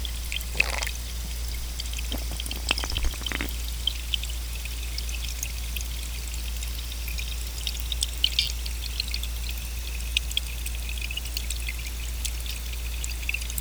At the top is a spectrogram of a complete series of sounds produced by the brown trout as shown in the video and recorded 25 October 2015 at the Bluestream Trout Hatcher, Cape Cod, MA.